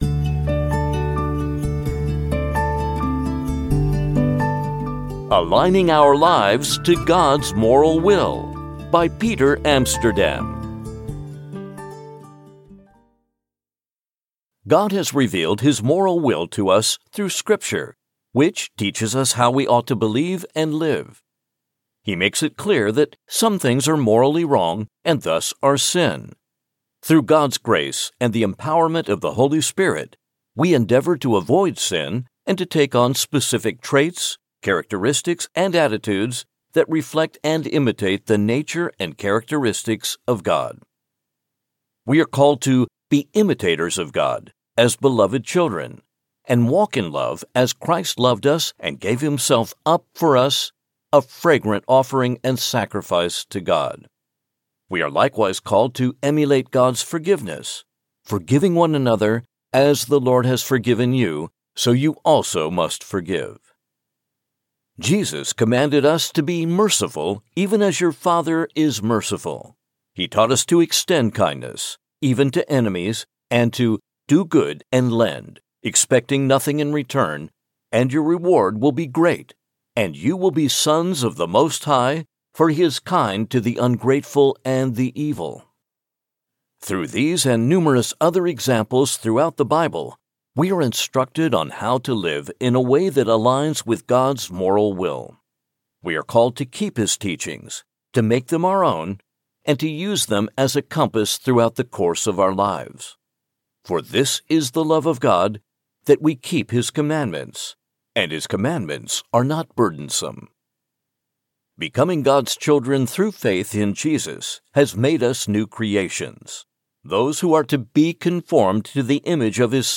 TFI_Devotional_Aligning_Our_Lives_to_Gods_Moral_Will.mp3